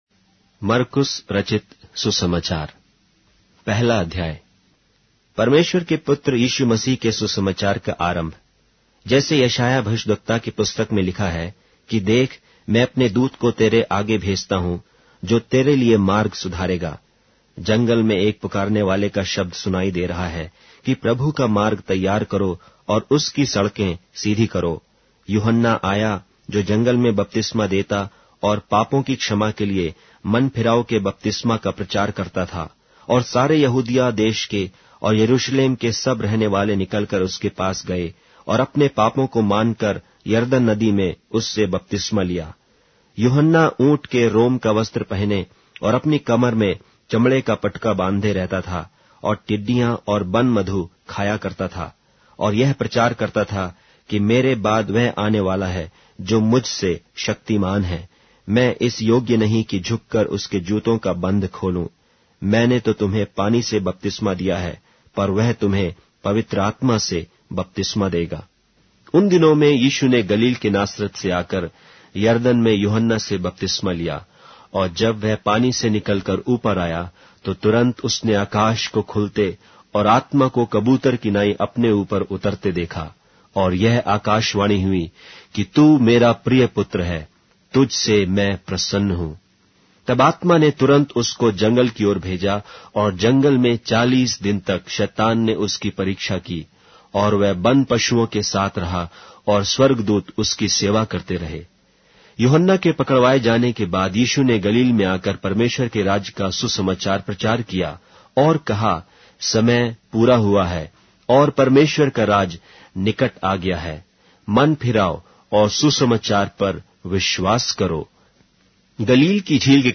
Hindi Audio Bible - Mark 6 in Pav bible version